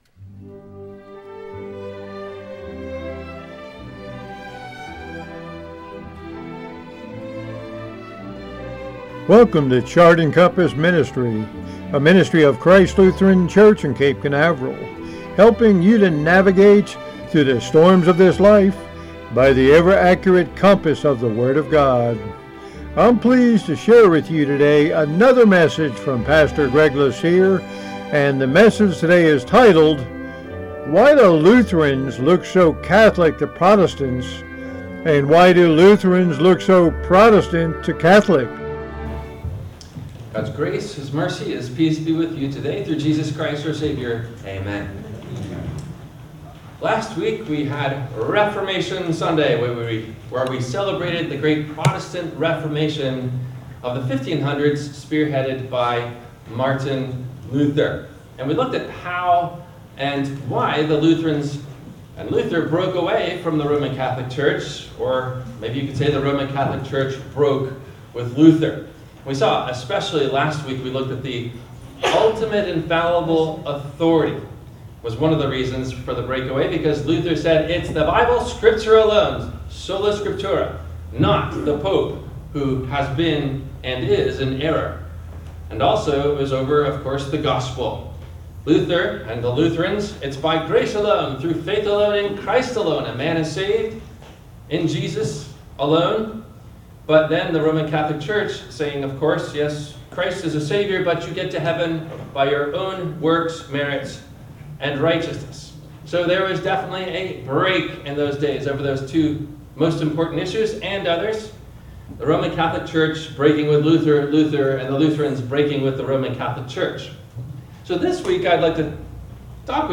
– WMIE Radio Sermon – November 24 2025 - Christ Lutheran Cape Canaveral